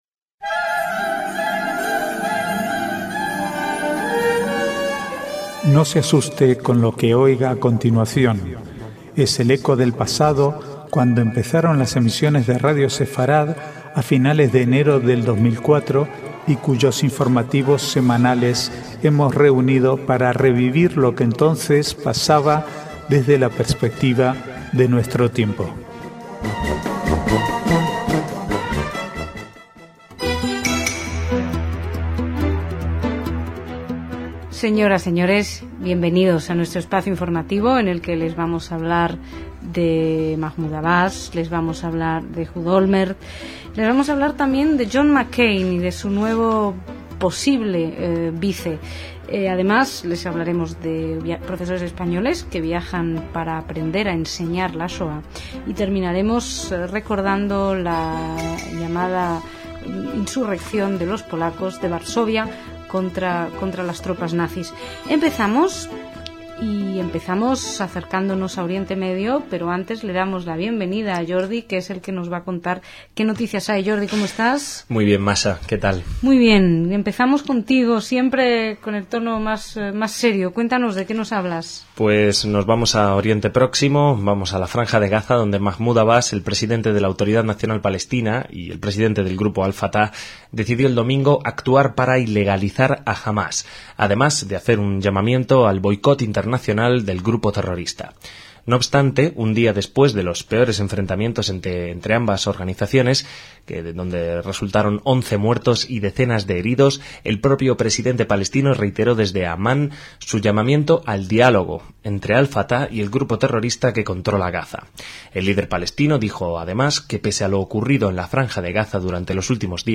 Archivo de noticias del 5 al 12/6/2008